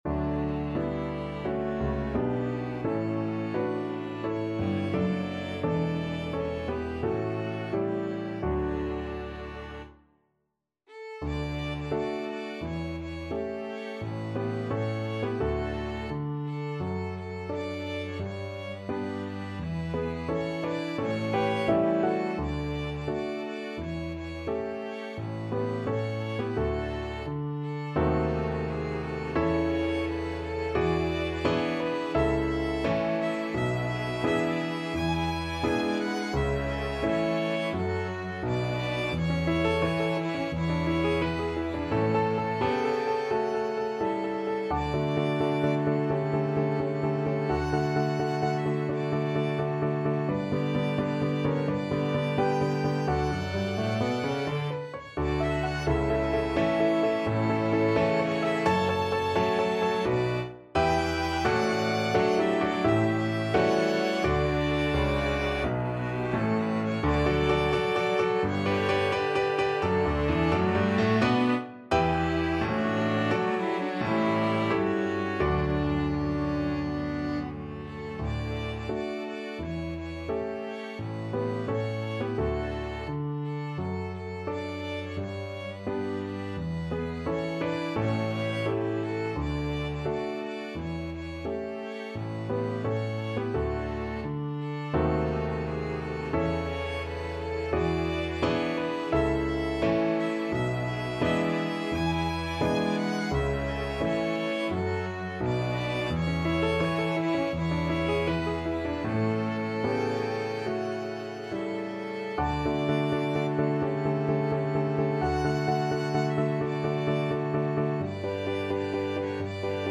ViolinViolin 2ViolaCelloPiano
4/4 (View more 4/4 Music)
Piano Quintet  (View more Intermediate Piano Quintet Music)
Classical (View more Classical Piano Quintet Music)